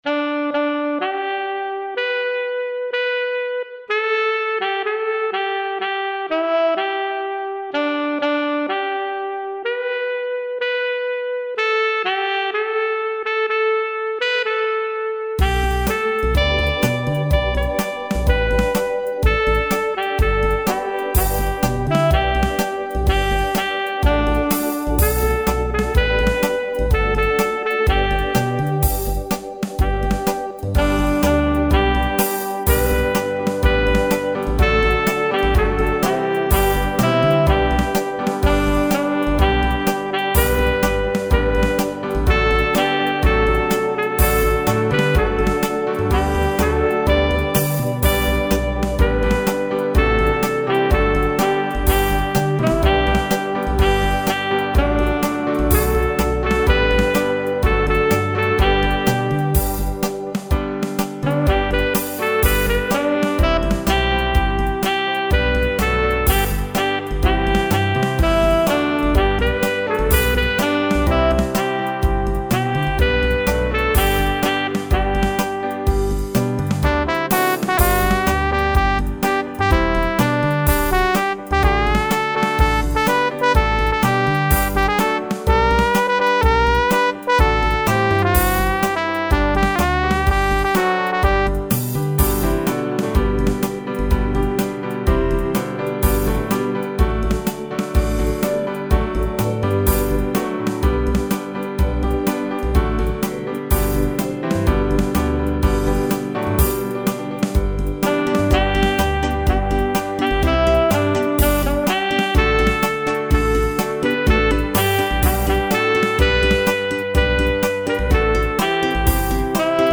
African Gospel.